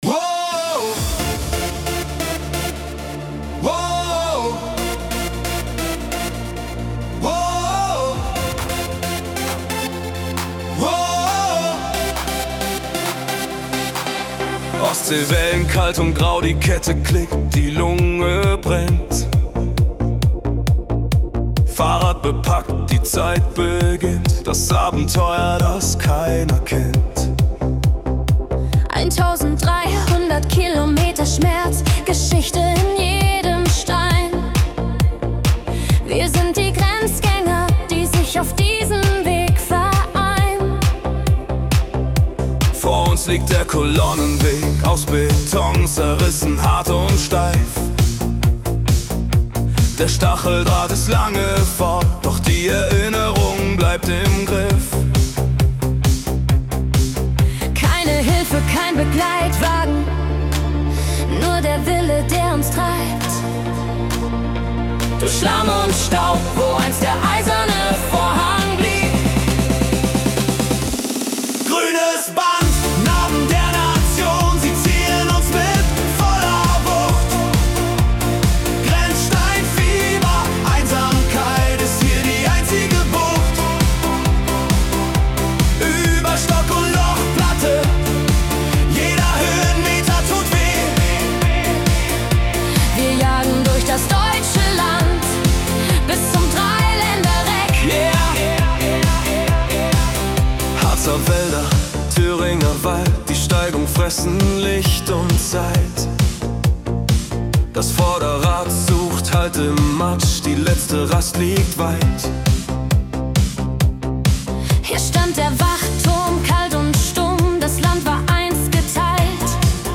Der stampft schon nach vorne raus … und ja, es bleibt „Geschmacksache“.